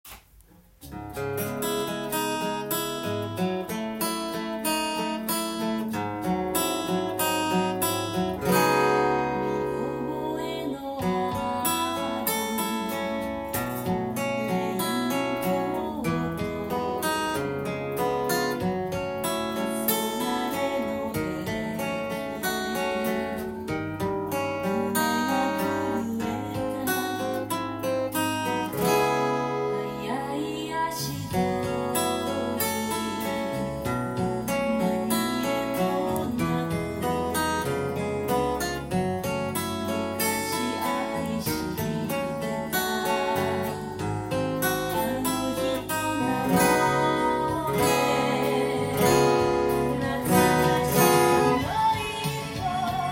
アルペジオTAB譜
音源に合わせて譜面通り弾いてみました
暗い雰囲気のする曲ではありますが、かなりカッコいい名曲です。
アコースティックギターで４カポで弾いていきます。
オルガンとユニゾンできるアルペジオです。
弦飛びが多いアルペジオなので非常に練習になります。